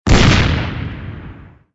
audio: Converted sound effects
MG_cannon_fire_alt.ogg